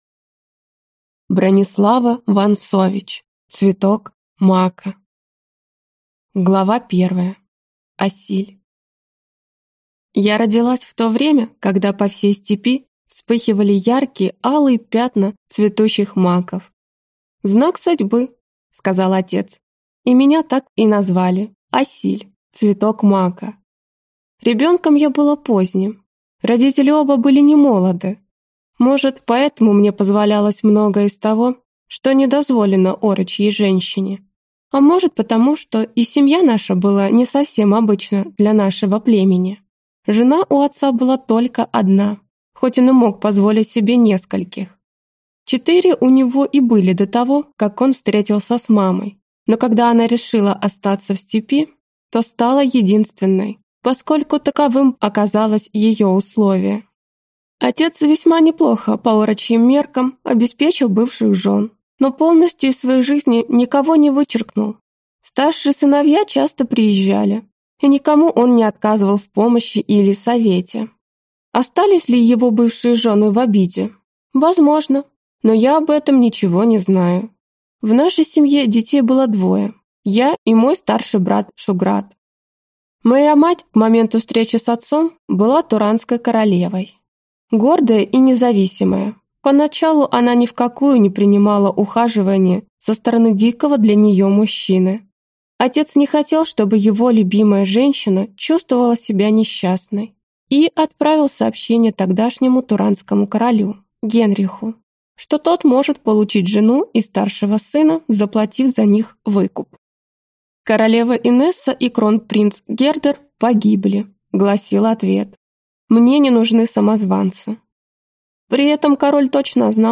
Aудиокнига Цветок мака